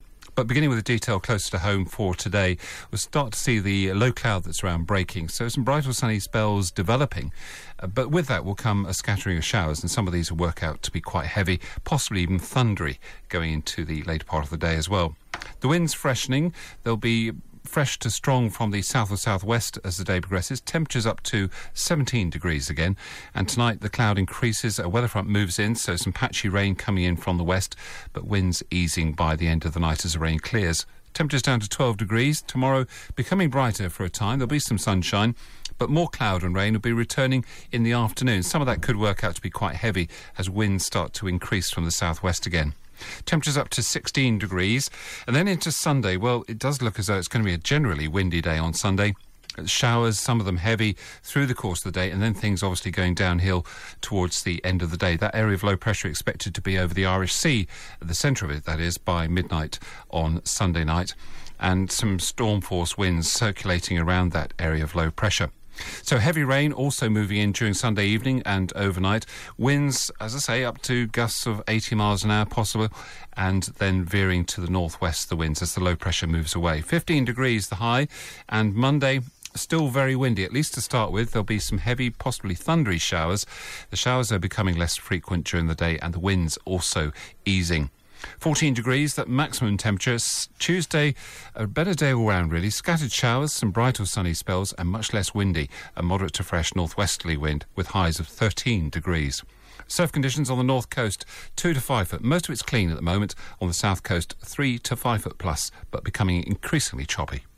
5 day forecast for Devon from 8.35AM on 25 October